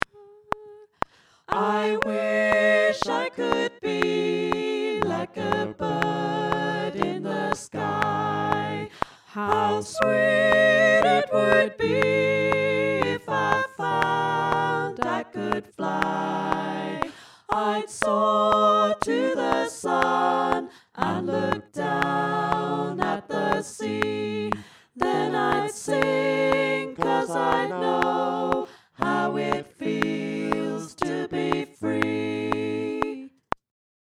I Wish I Knew All parts